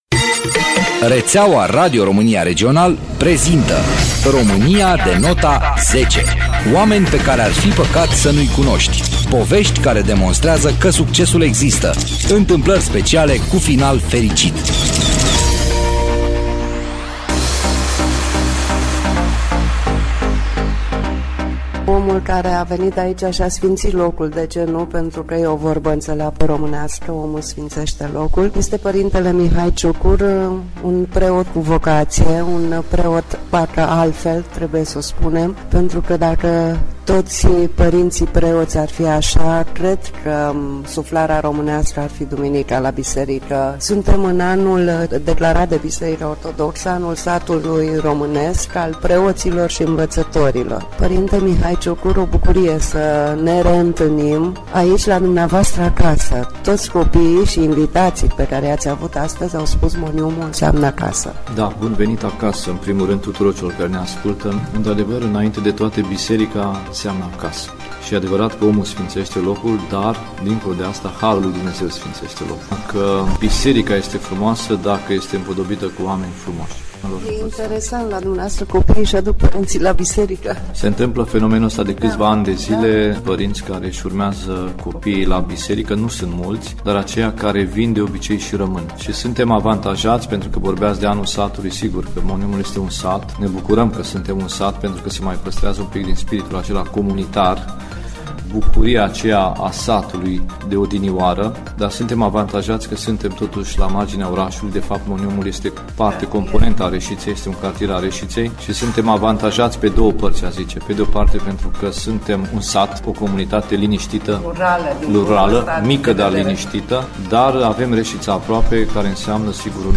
Radio Reșița: Dragostea face diferența